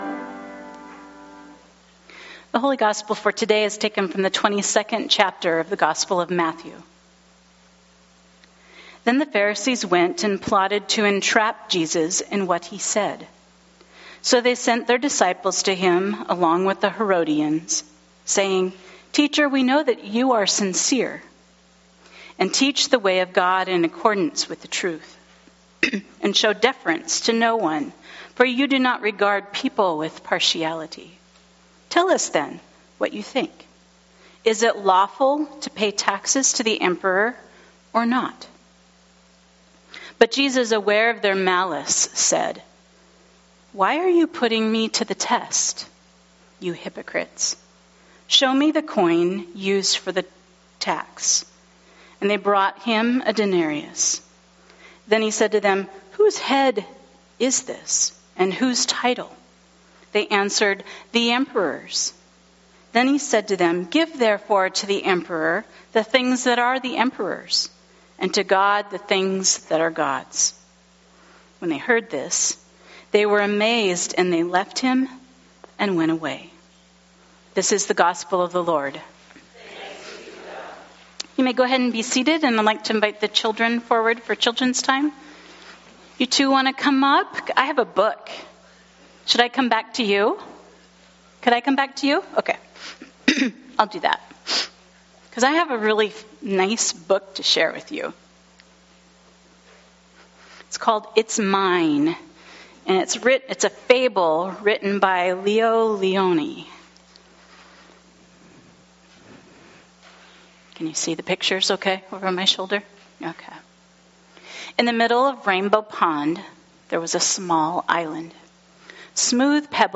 To listen to the Gospel and sermon, click the link below.